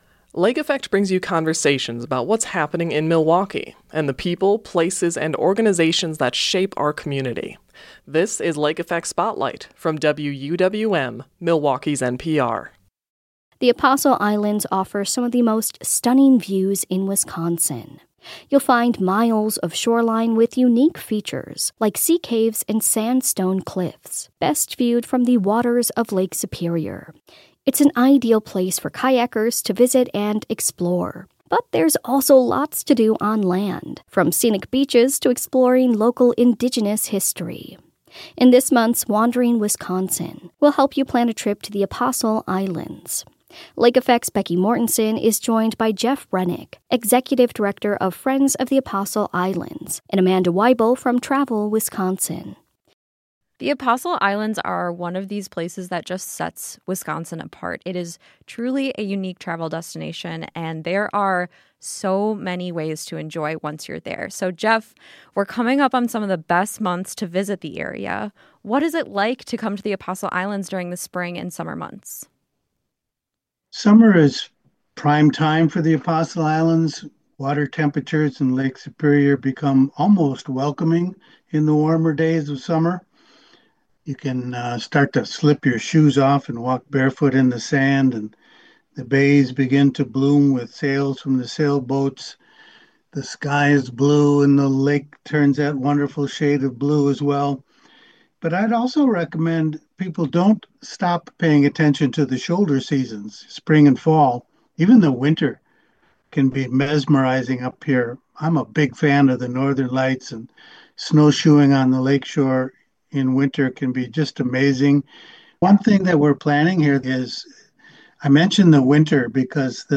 The Lake Effect Spotlight podcast features some of our favorite conversations about the people, places and organizations that shape Milwaukee.Learn more about Lake Effect here.